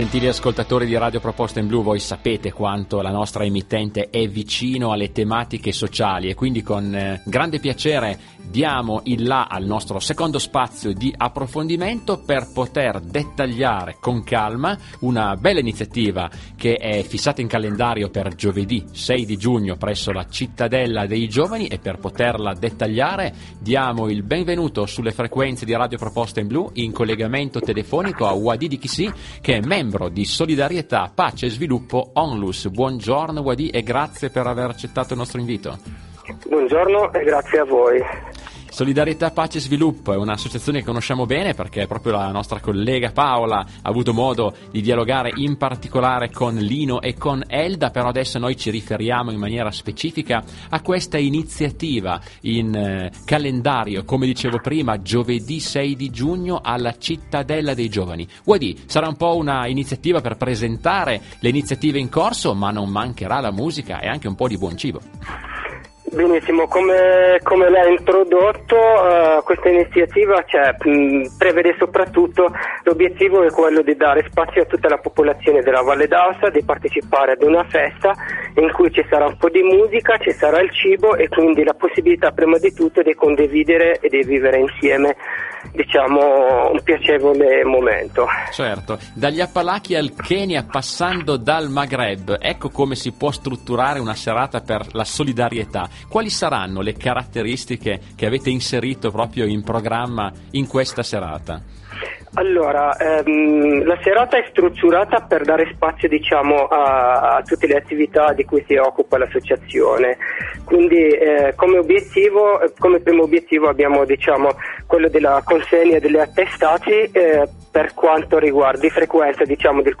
In questa sezione troverete le trasmissioni radiofoniche a cui SPS ha partecipato, i video sui nostri progetti o quelli realizzati durante gli eventi che organizziamo.